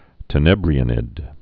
(tə-nĕbrē-ə-nĭd, tĕnə-brī-)